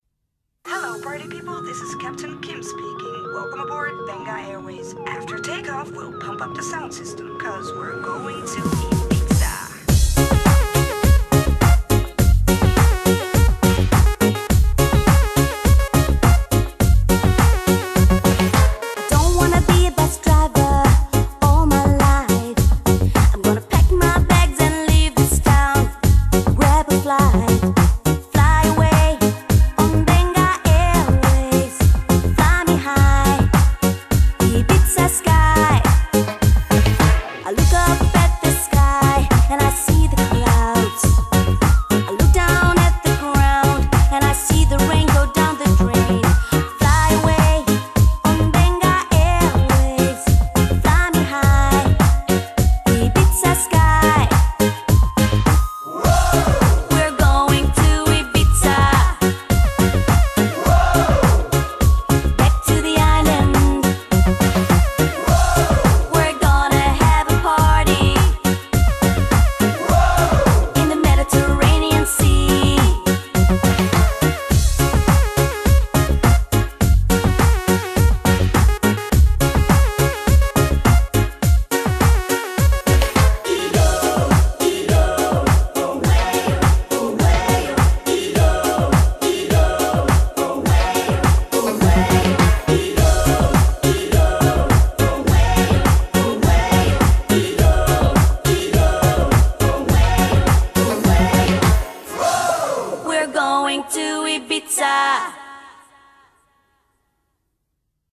BPM104
MP3 QualityMusic Cut